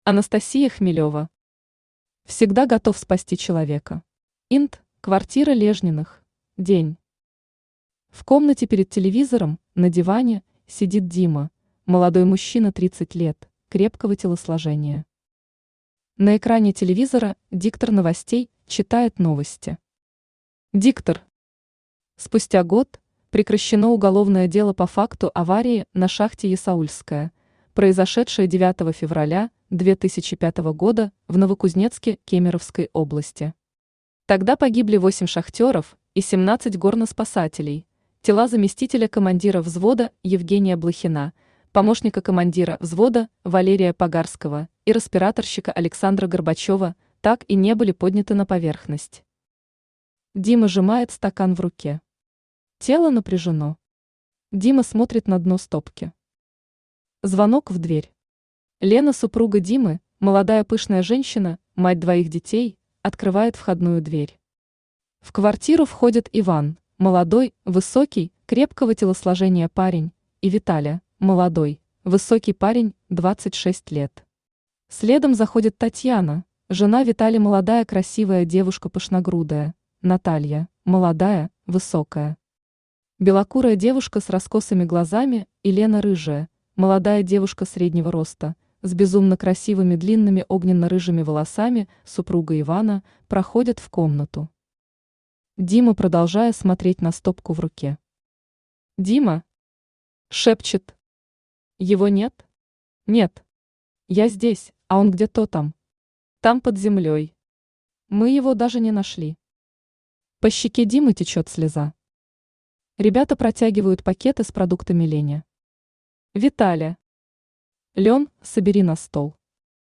Аудиокнига Всегда Готов Спасти Человека | Библиотека аудиокниг
Aудиокнига Всегда Готов Спасти Человека Автор Анастасия Хмелева Читает аудиокнигу Авточтец ЛитРес.